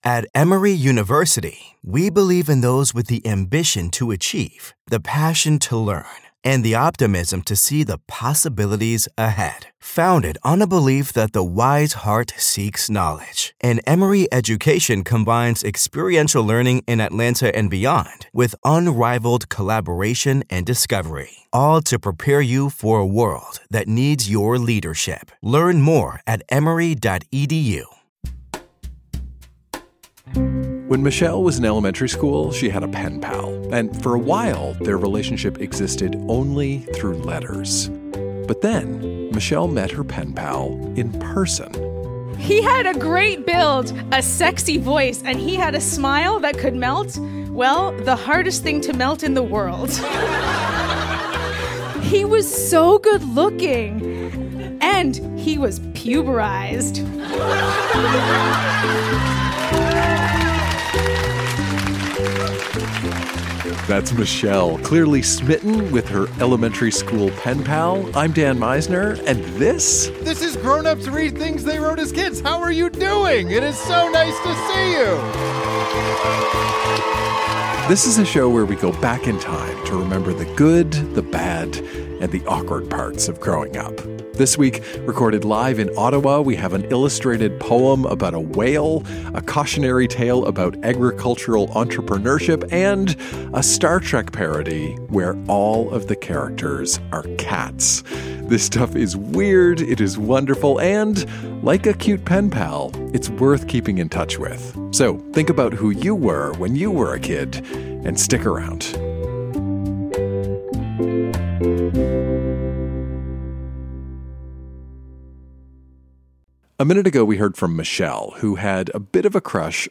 Recorded live at Yuk Yuk's in Ottawa.